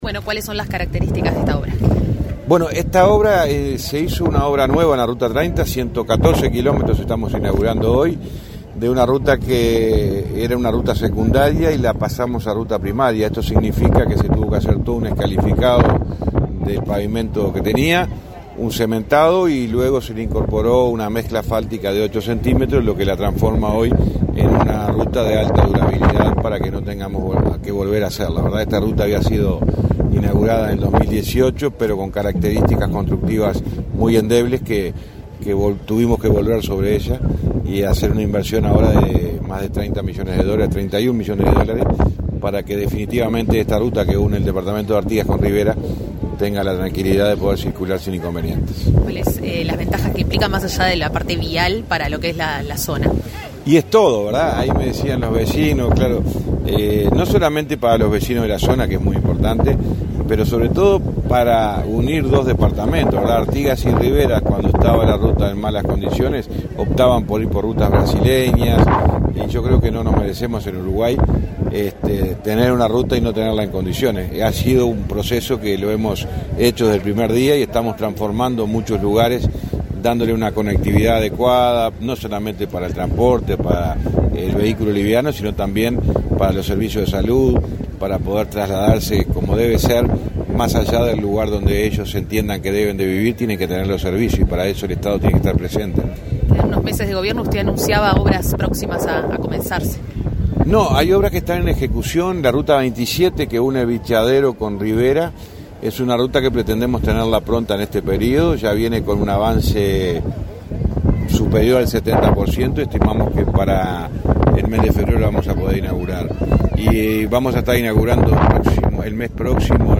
Declaraciones del ministro de Transporte y Obras Públicas, José Luis Falero
Declaraciones del ministro de Transporte y Obras Públicas, José Luis Falero 09/10/2024 Compartir Facebook X Copiar enlace WhatsApp LinkedIn Tras la inauguración de obras realizadas en la ruta n.º 30, en el tramo comprendido entre Artigas y Tranqueras, este 9 de octubre, el ministro de Transporte y Obras Públicas, José Luis Falero, realizó declaraciones a la prensa.